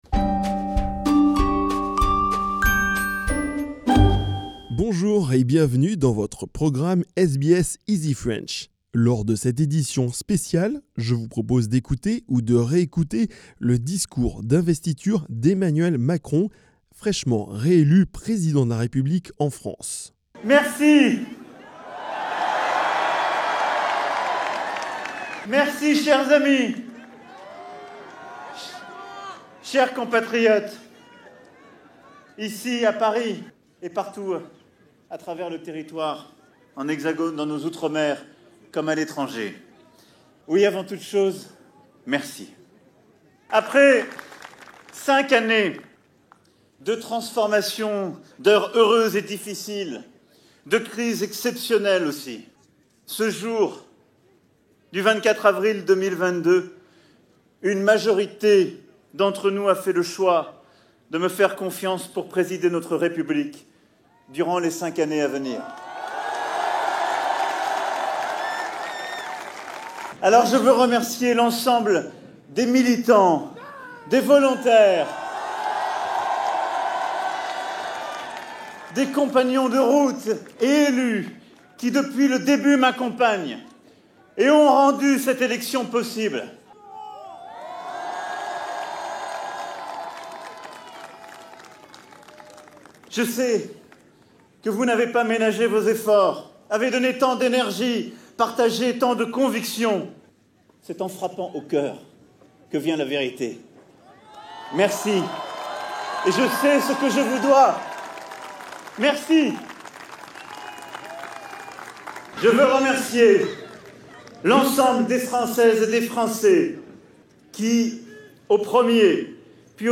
Ecoutez ou Réécoutez le discours du President Emmanuel Macron le soir de sa victoire.